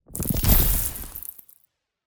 Free Frost Mage - SFX
glacial_spikes_08.wav